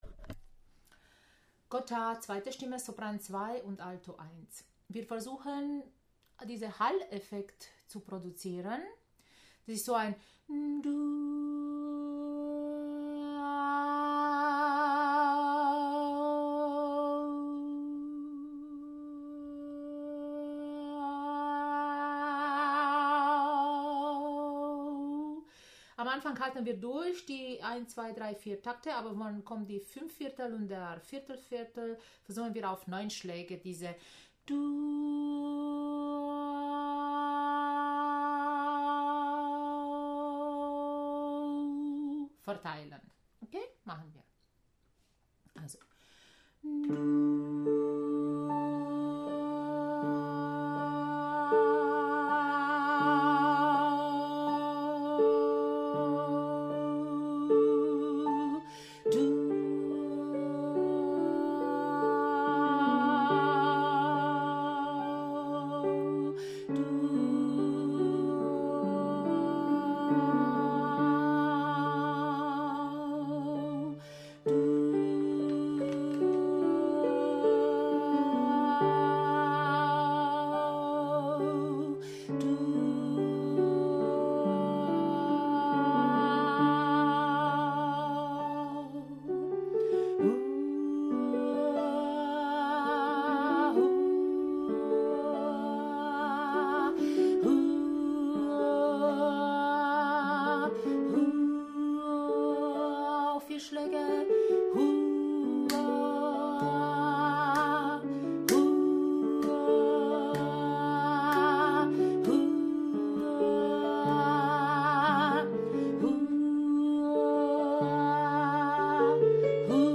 Gota – Sopran2 + Alt1